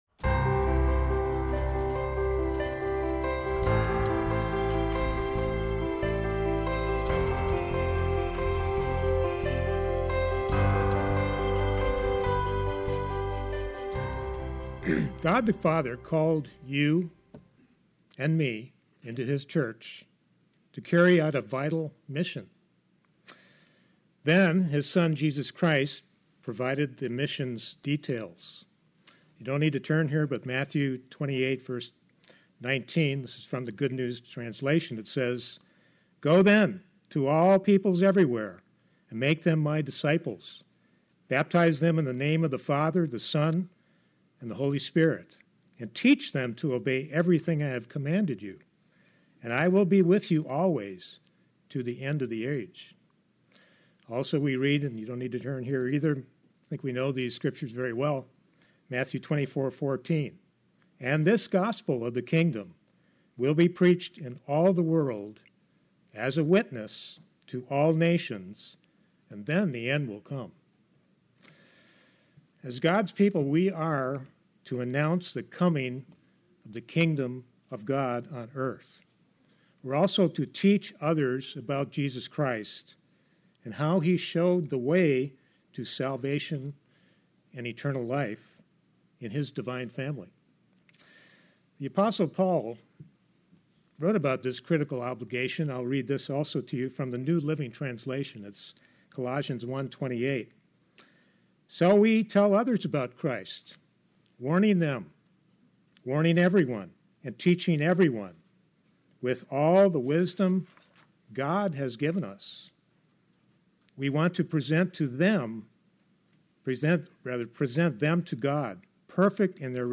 About 2,600 years ago, the prophet Habakkuk had comparable thoughts and expressed them by way of questions to God. In this message we’ll seek to understand what God explained to Habakkuk—which can benefit and guide us today.